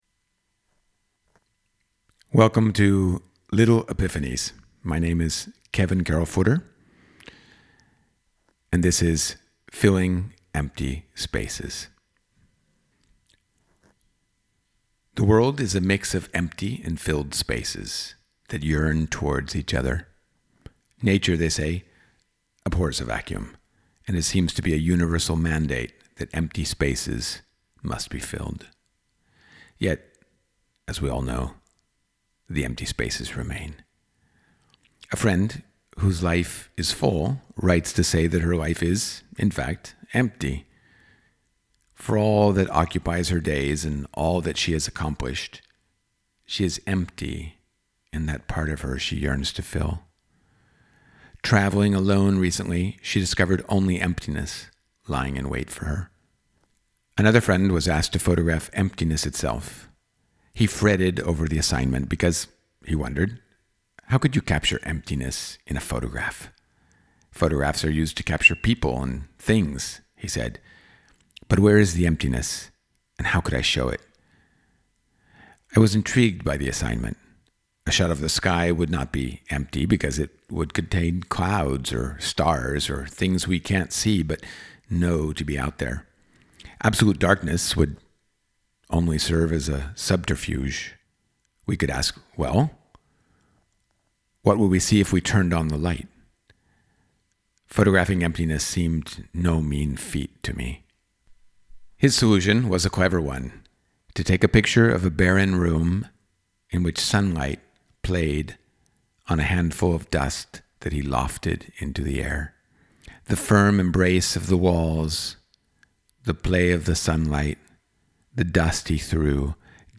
Plus, I DO fancy being read to, especially by your warm familiar voice.
It felt like a much richer experience, poetic and almost musical.